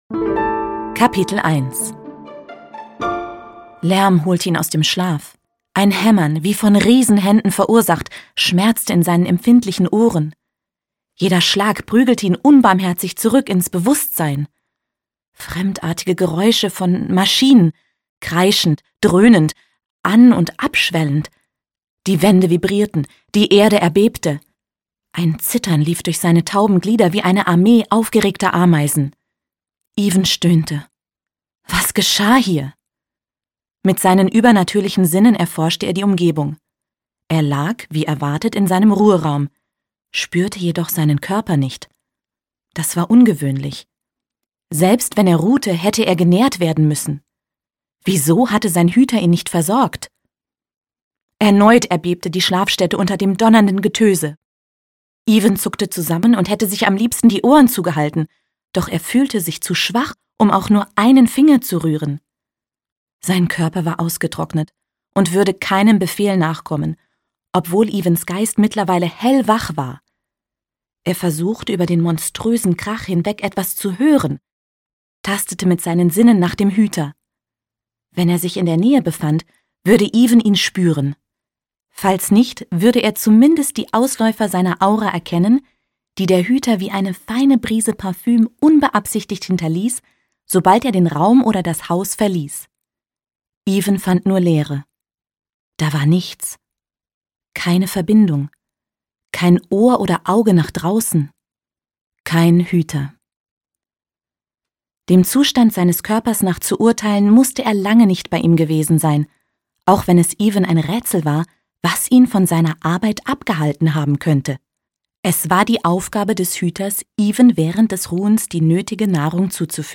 Das Erbe des Hüters - Sandra Florean - Hörbuch